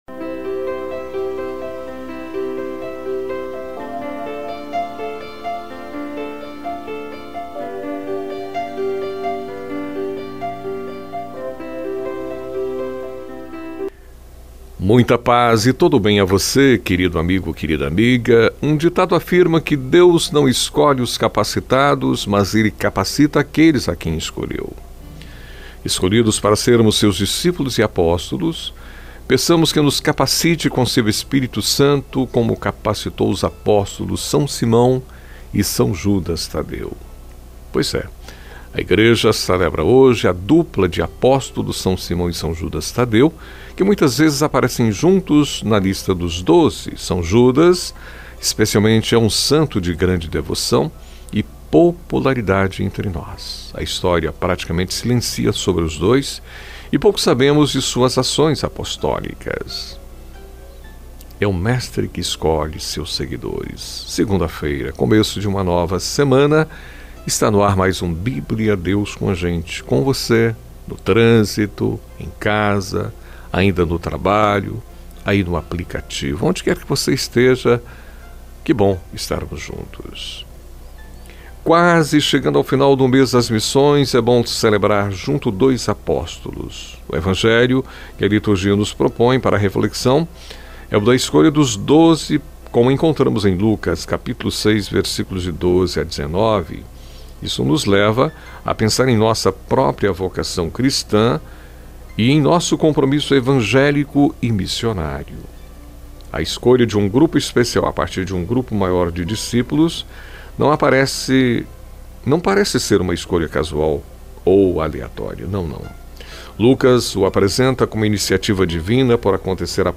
É um momento de reflexão diário com duração de aproximadamente 5 minutos, refletindo o evangelho do dia, indo ao ar de segunda a sexta, na voz do locutor